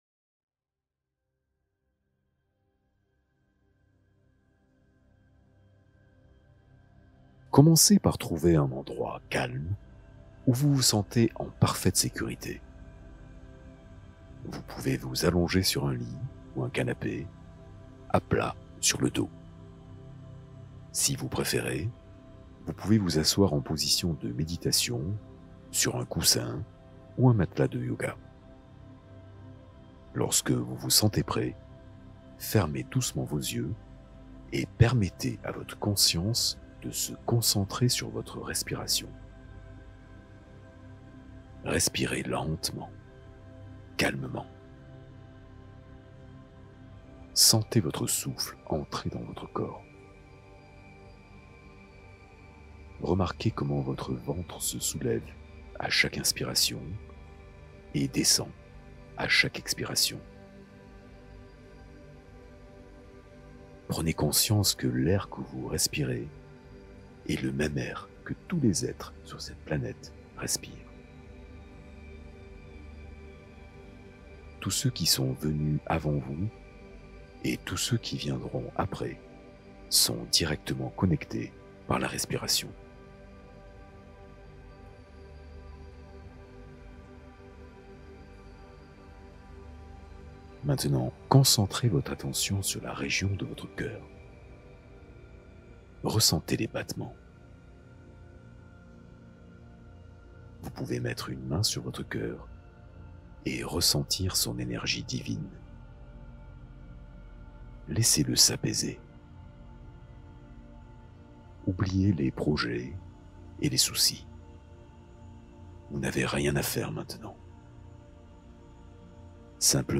Hypnose pour dépasser la peur du rejet et te libérer